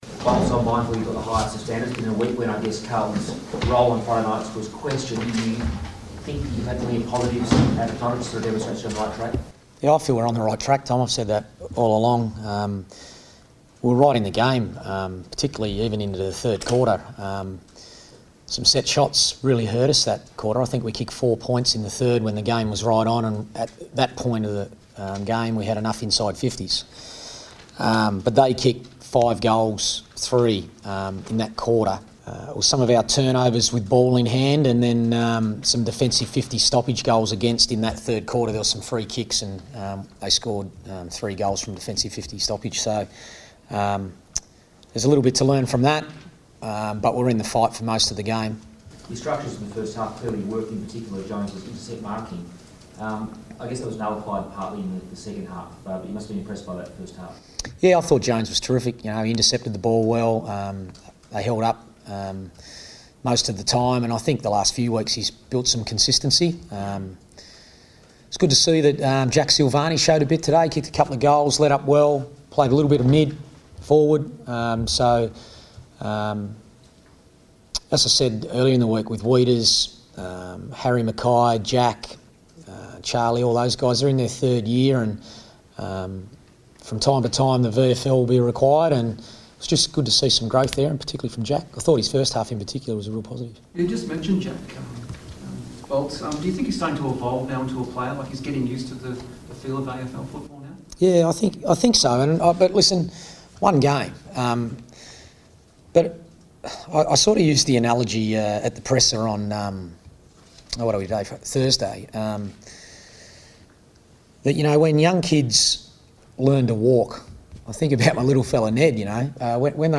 Carlton coach Brendon Bolton speaks to the media after the Blues' 30-point loss to Sydney at the SCG.